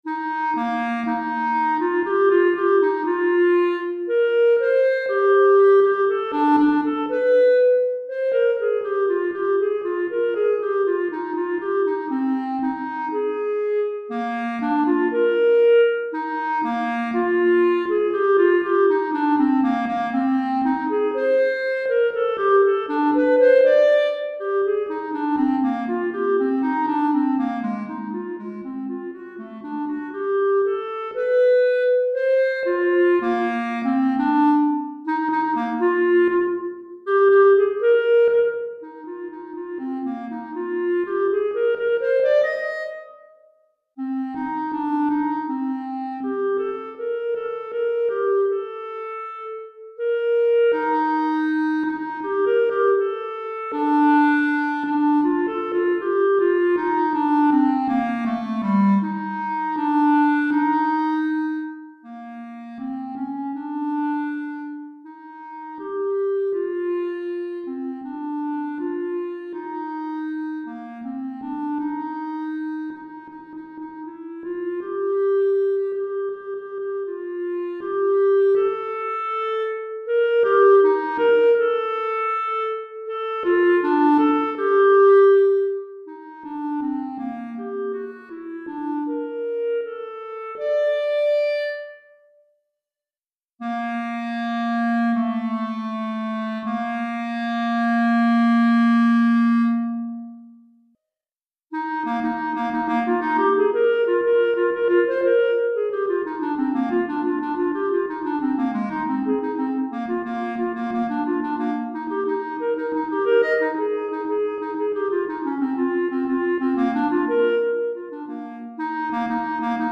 Pour Clarinette solo DEGRE FIN DE CYCLE 1
Clarinette solo